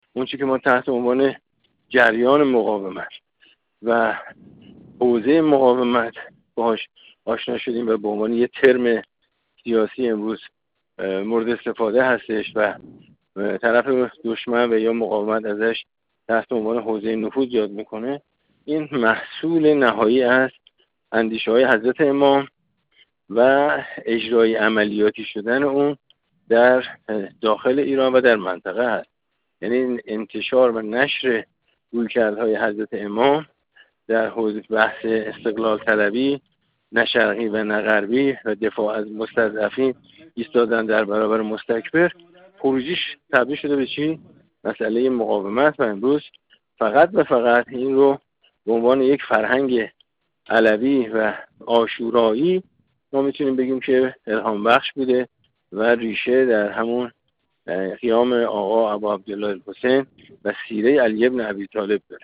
ابوالفضل ظهره‌وند، نماینده مردم تهران در مجلس شورای اسلامی
گفت‌وگو